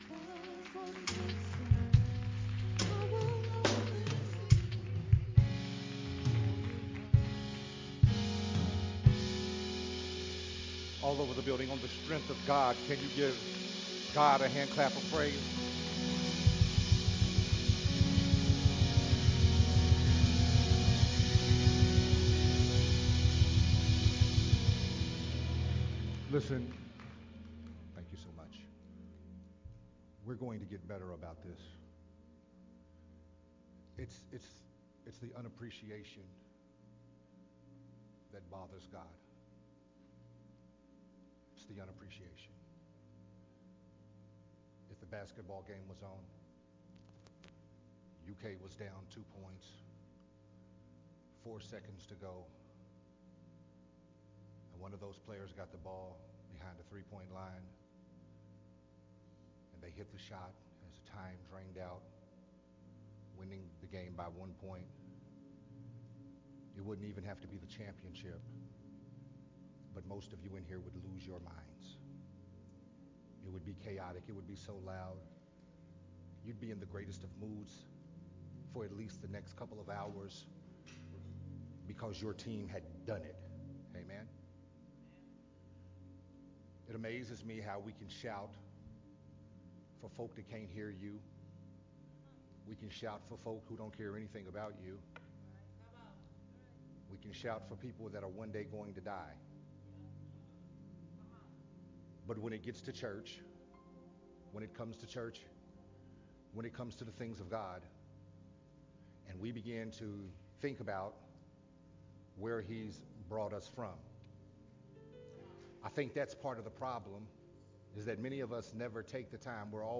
Recorded at Unity Worship Center on August 8th, 2021.